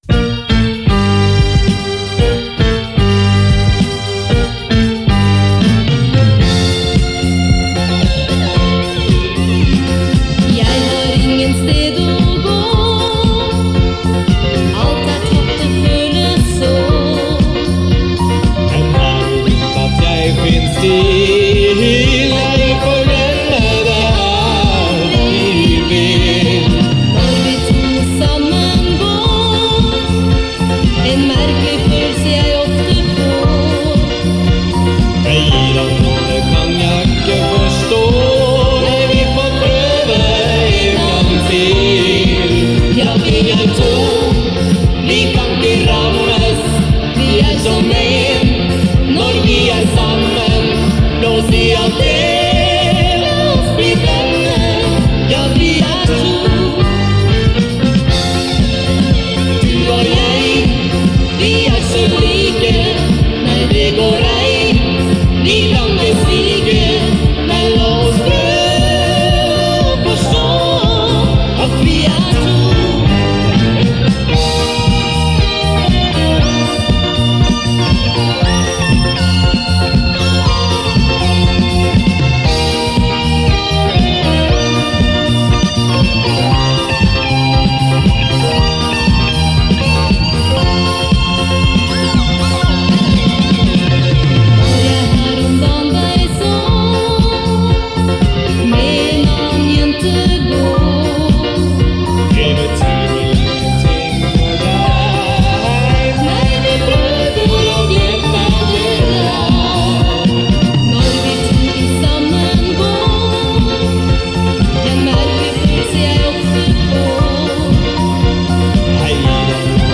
Innspilt i BEL Studio, Oslo og på diverse andre steder.